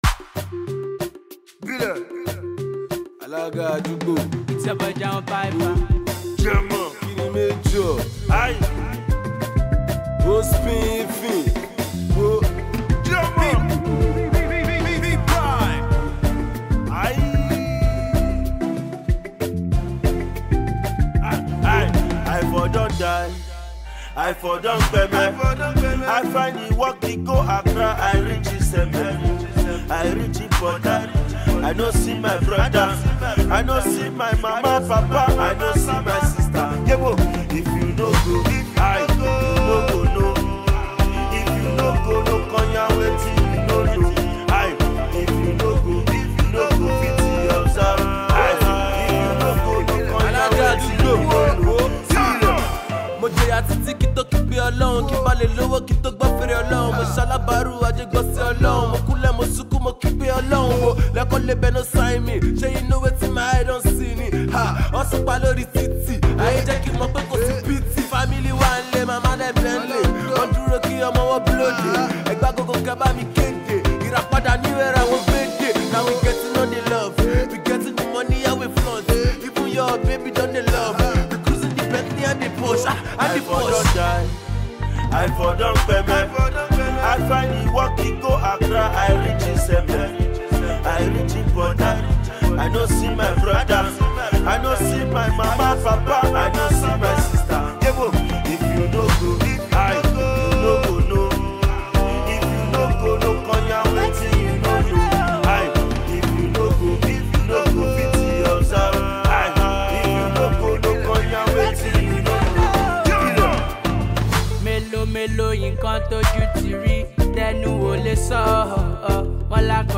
street hop